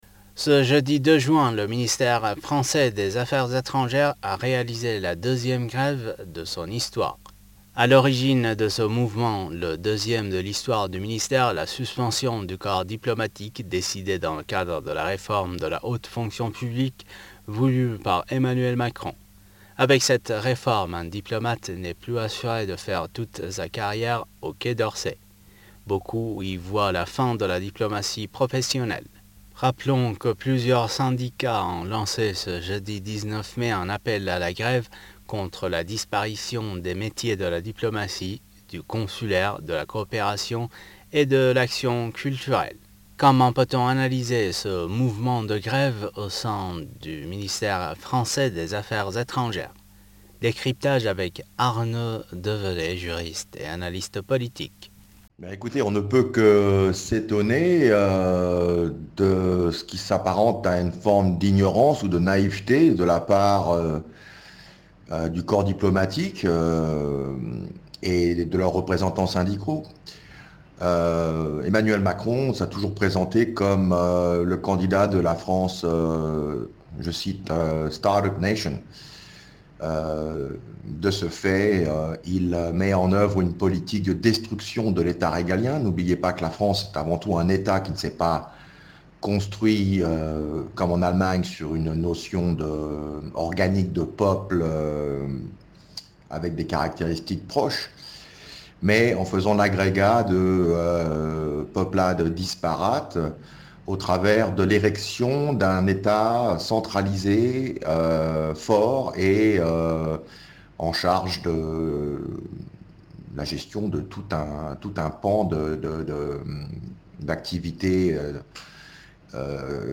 juriste et analyste politique.